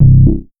BUBBLE BASS.wav